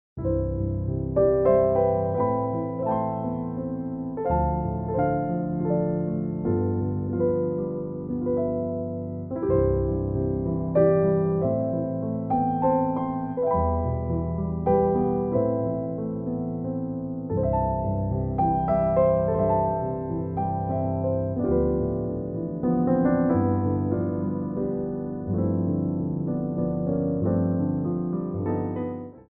Ronds de Jambé à Terre
3/4 (16x8)